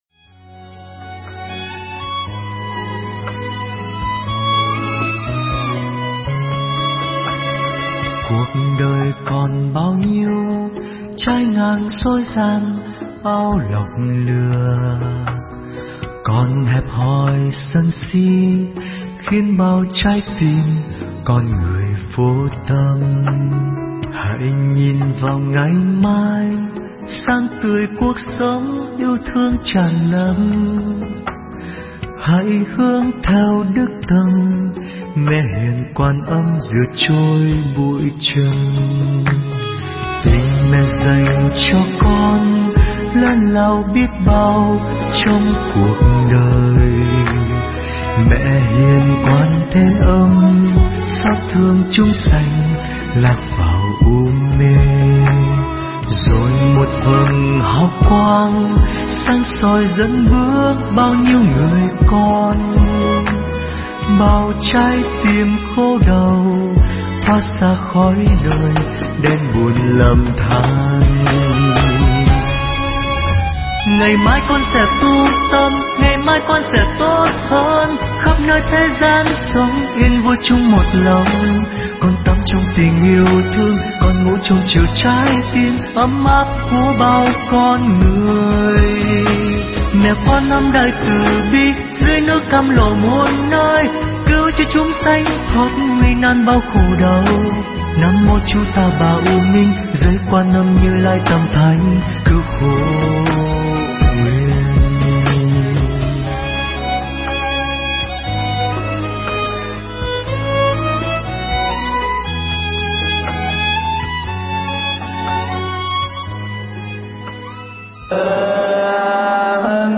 Nghe Mp3 thuyết pháp Các Phép Quán Hóa Giải Phiền Não
Mp3 Pháp Thoại Các Phép Quán Hóa Giải Phiền Não